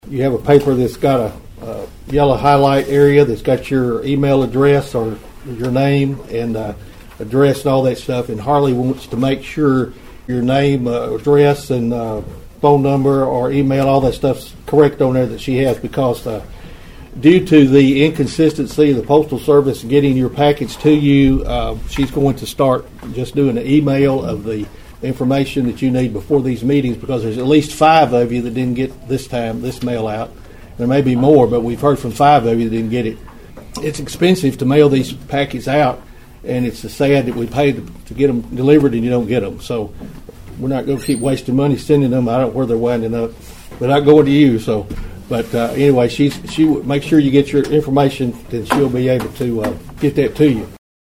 Mayor Carr explained the issue to the board members at the recent county meeting.(AUDIO)